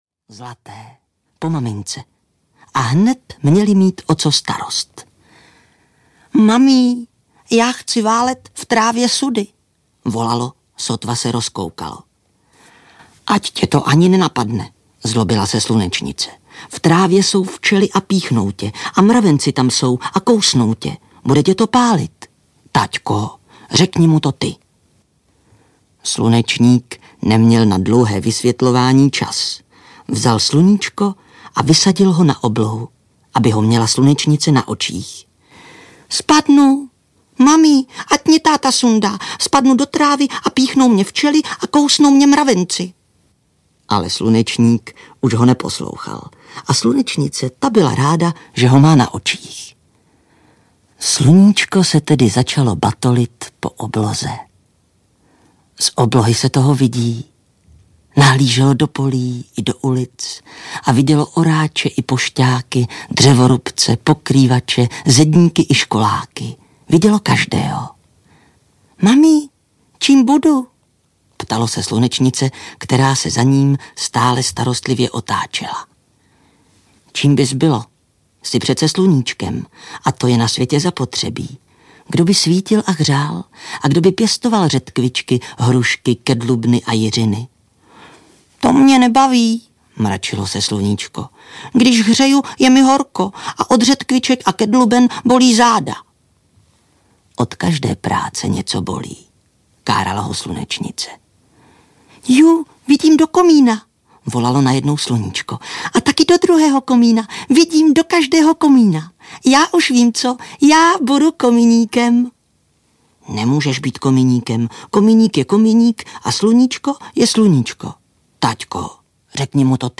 Václav Neckář není jen legendární zpěvák, ale také skvělý vypravěč.
Ukázka z knihy
• InterpretVáclav Neckář